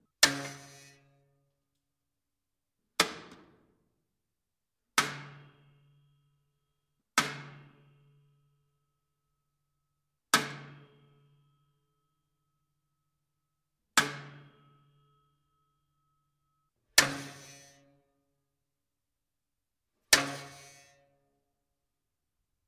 SwitchSpringElectric EH1156
Switch with spring electricity box electrical device